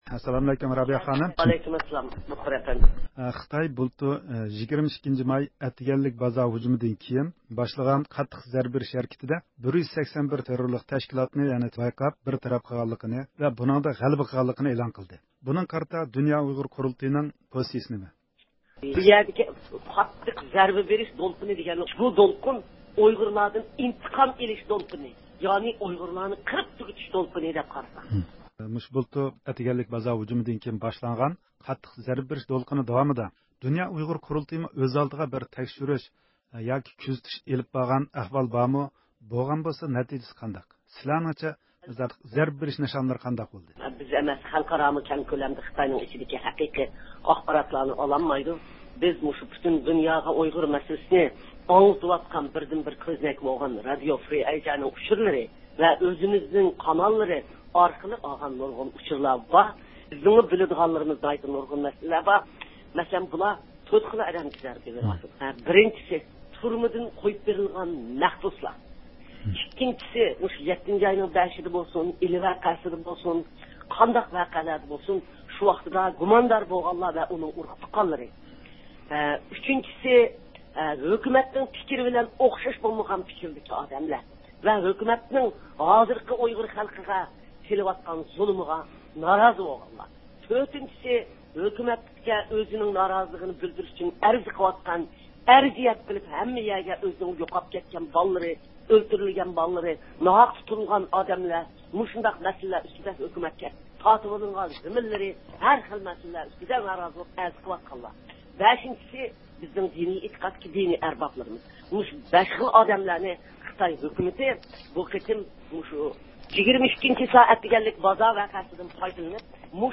بۇ مۇناسىۋەت بىلەن ئۇيغۇر مىللىي ھەرىكىتى رەھبىرى رابىيە قادىر خانىم رادىئومىزدا سۆز قىلىپ، خىتاينىڭ مەزكۇر بايانلىرىغا پوزىتسىيە بىلدۈردى، رابىيە خانىم سۆزىدە ئالدى بىلەن ئۇيغۇر رايونىدا بۇلتۇر داۋام قىلغان قاتتىق زەربە بېرىش دولقۇنىنىڭ ماھىيەتتە بۇلتۇر يۈز بەرگەن ئەتىگەنلىك بازار ۋەقەسى سەۋەبلىك خىتاي دۆلىتىنىڭ ئۇيغۇر مىللىتىدىن ئىنتىقام ئېلىش دولقۇنى ئىكەنلىكىنى ئىلگىرى سۈردى.